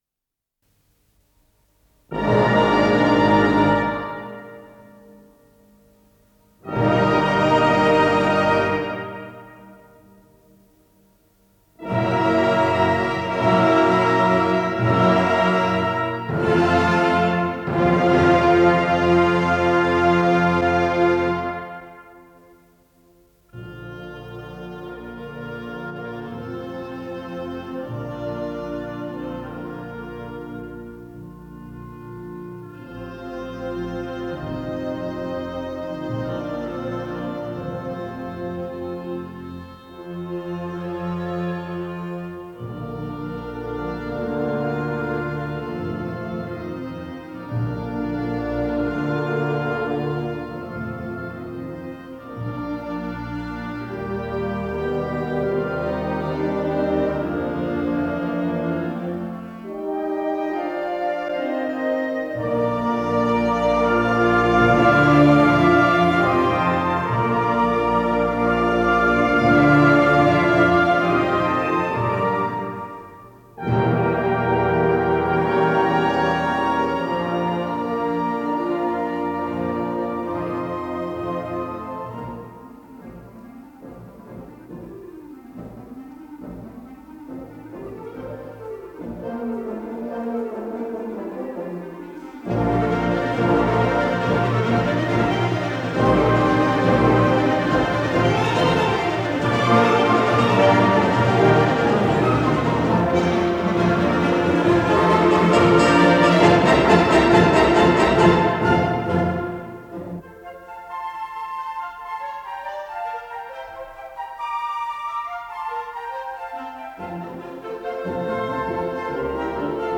ПодзаголовокСи бемоль мажор
ИсполнителиГосударственный духовой оркестр РСФСР
ВариантДубль моно